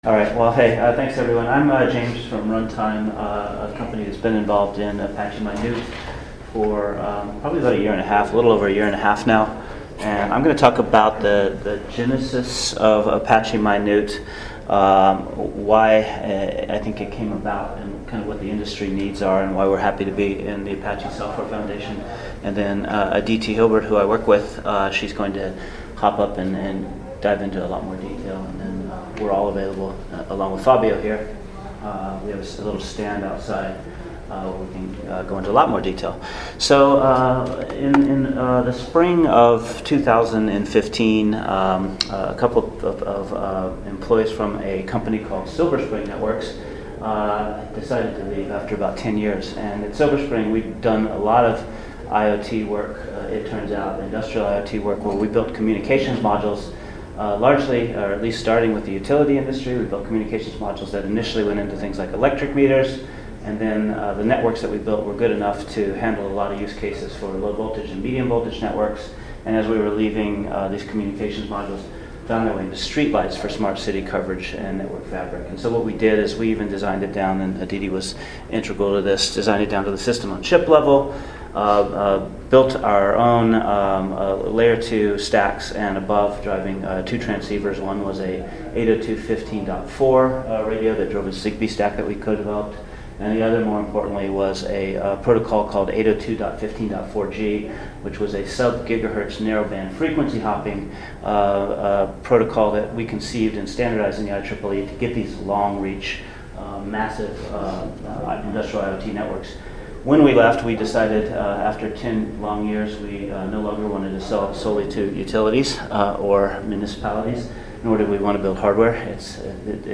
ApacheCon Miami 2017 – Apache Mynewt – ASF’s First Embedded OS Project